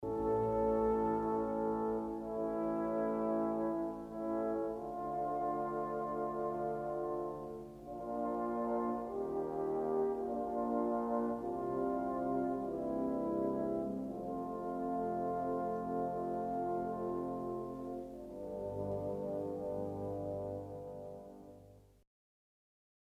controfagotto in orchestra
controfagotto_solo.mp3